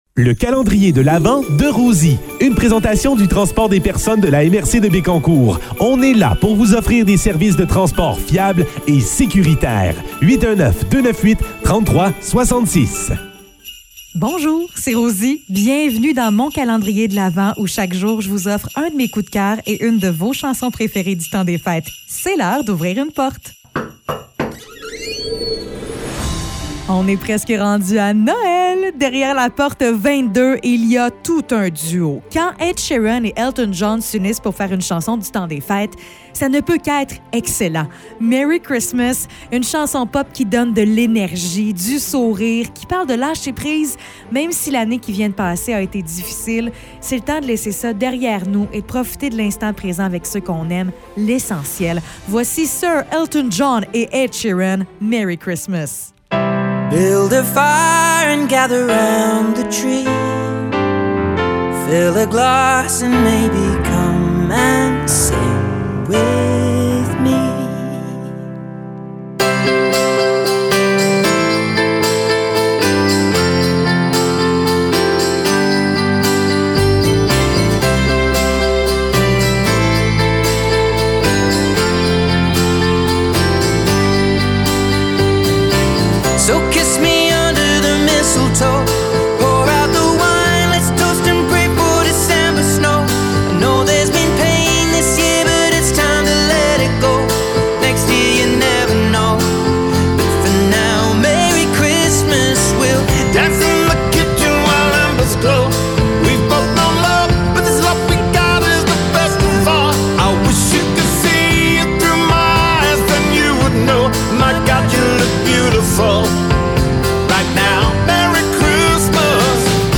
chanson du temps des fêtes